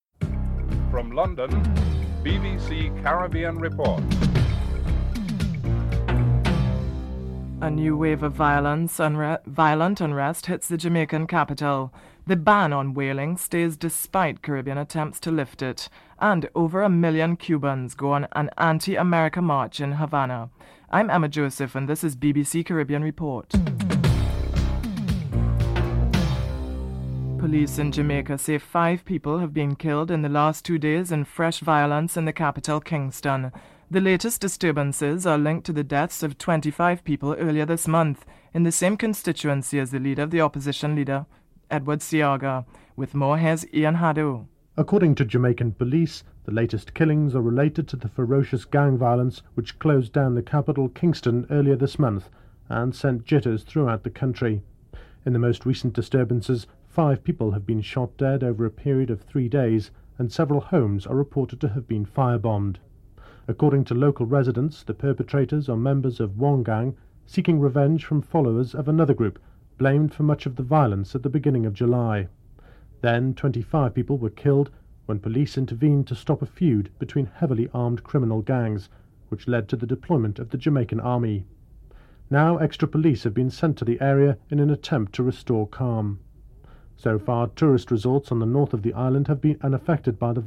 Leaders from the Organisation of Eastern Caribbean States have wrapped up their Summit in Dominica. Prime Ministers Ralph Gonsalves and Keith Mitchell are interviewed.